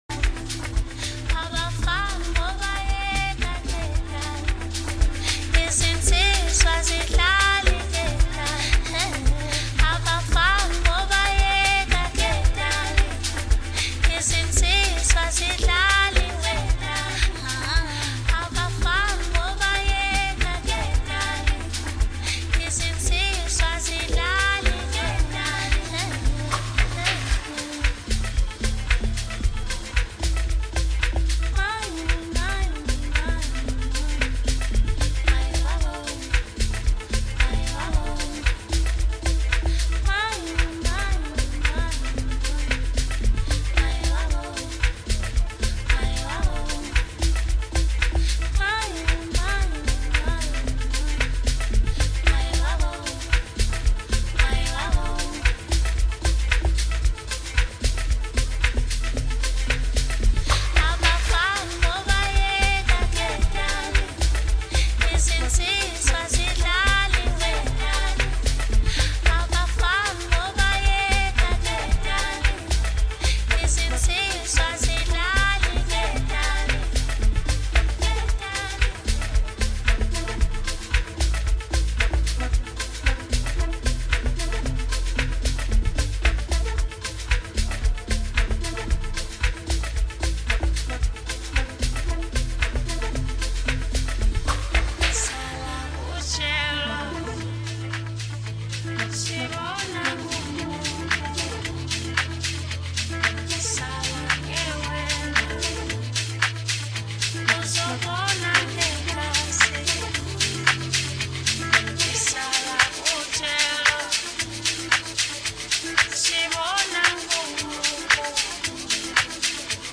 For those who would love to have a taste of his melodic set.